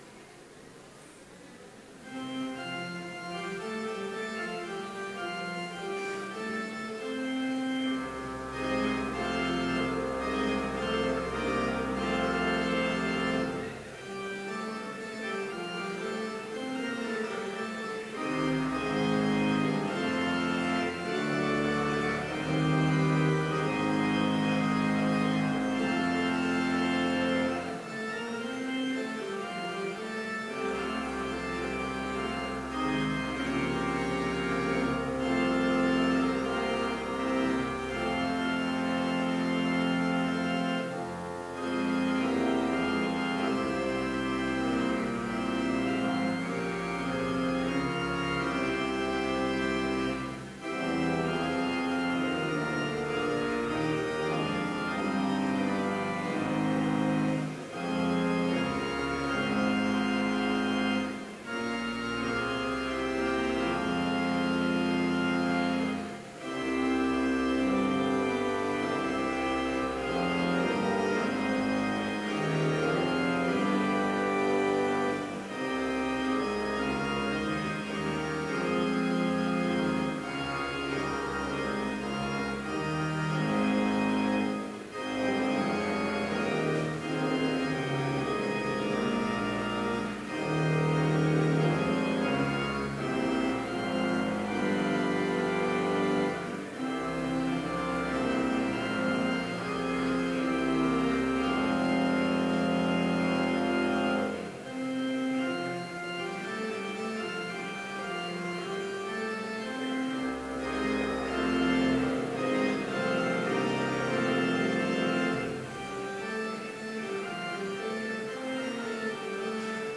Complete service audio for Chapel - May 10, 2016